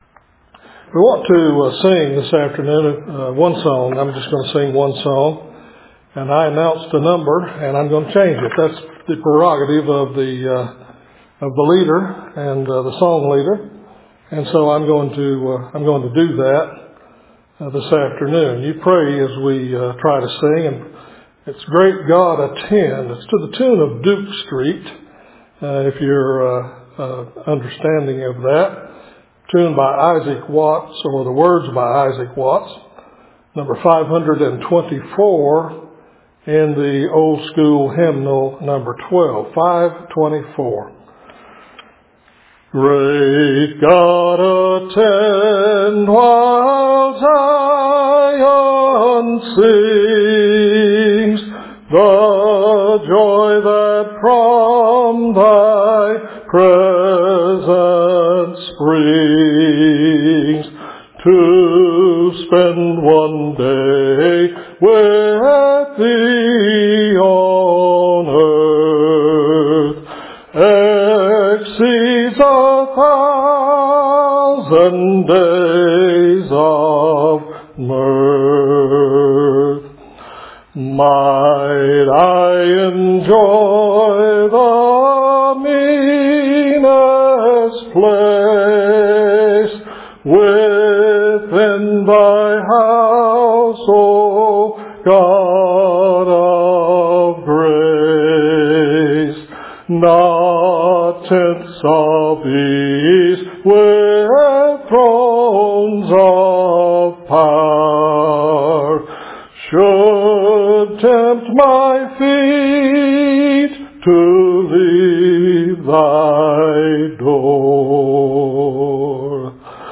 Sermon or written equivalent